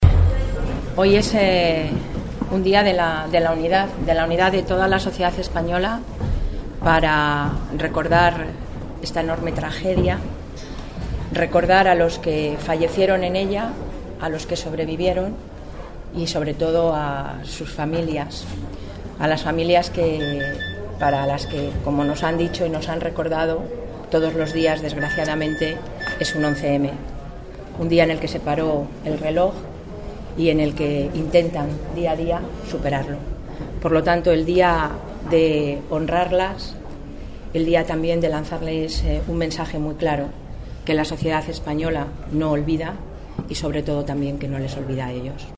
Declaraciones de Soraya Rodríguez en el homenaje a las víctimas del 11M en el décimo aniversario 11/03/2014